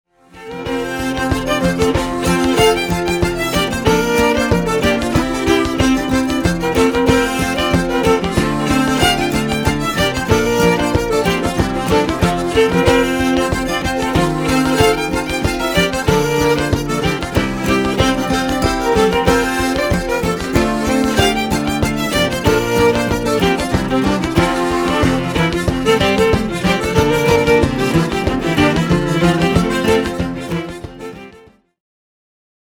Compositeur et multi-instrumentiste
Un panorama à la fois personnel et fidèle aux traditions